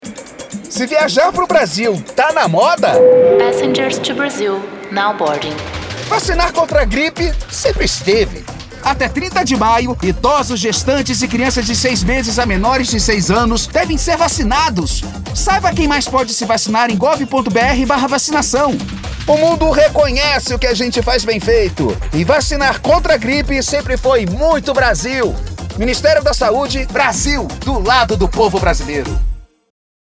Áudio - Spot 30s - Campanha Vacinação contra a gripe - 1.1mb .mp3 — Ministério da Saúde